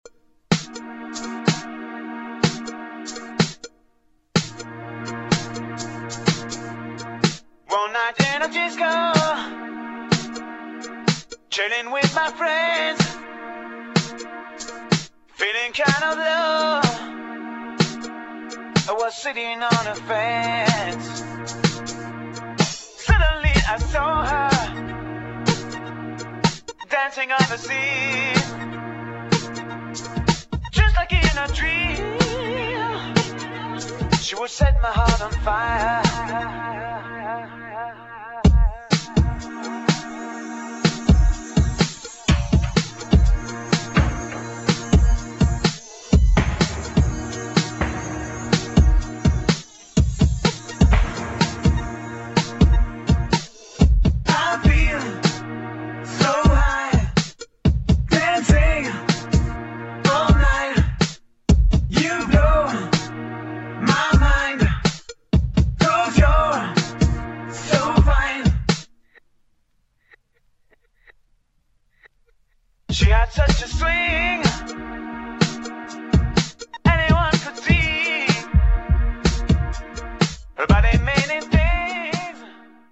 [ FRENCH HOUSE ]